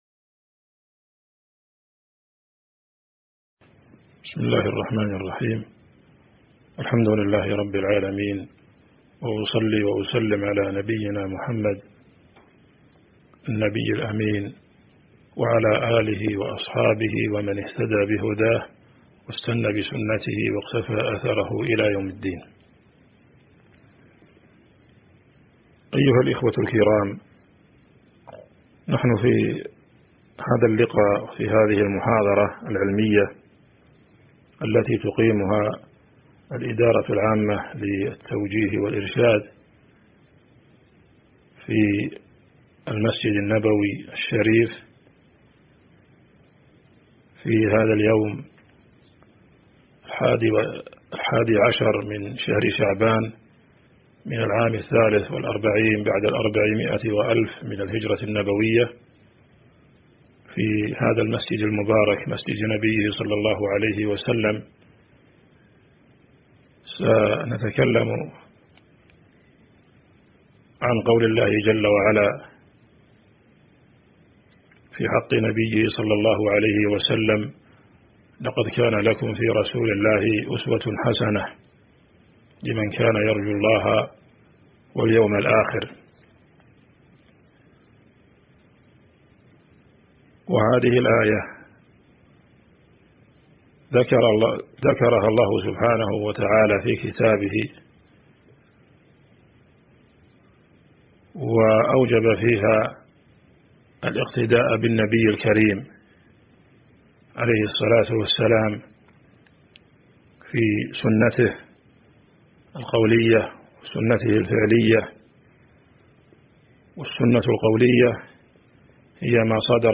تاريخ النشر ١١ شعبان ١٤٤٣ هـ المكان: المسجد النبوي الشيخ